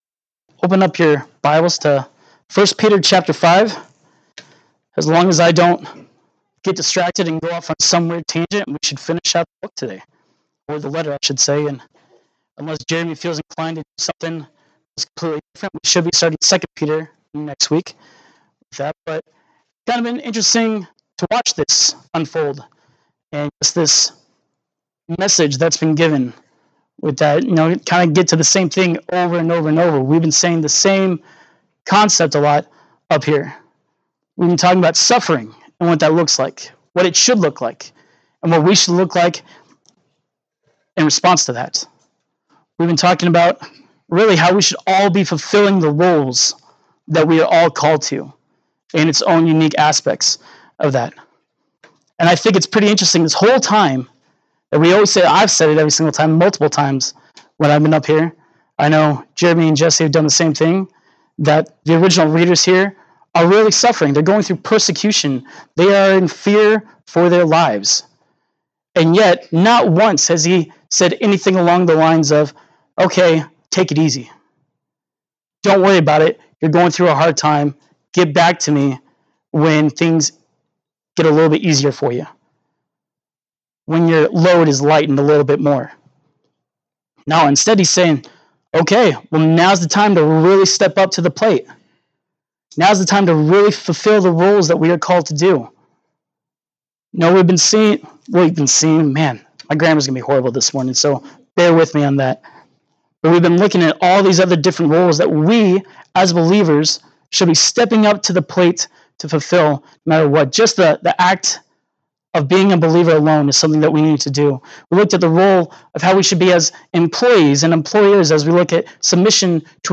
1 Peter 5:6-14 Service Type: Sunday Morning Worship « 1 Peter 5:1-5